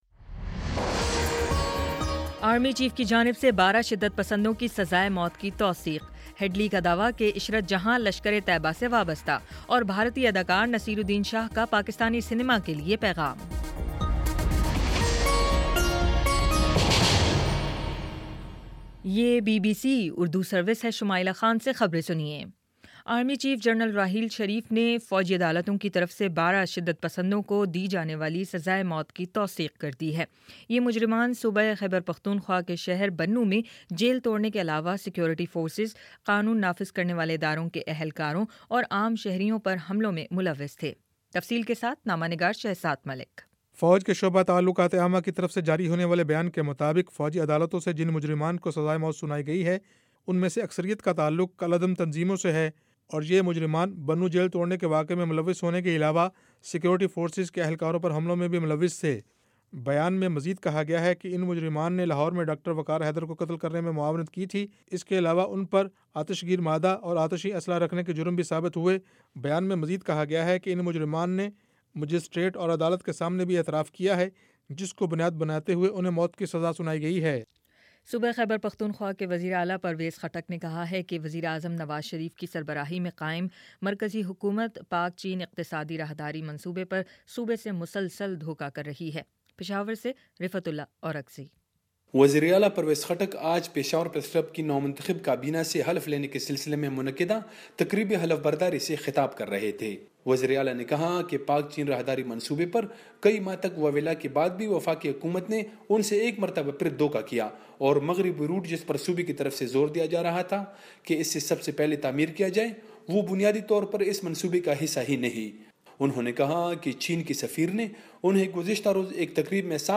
فروری 11 : شام چھ بجے کا نیوز بُلیٹن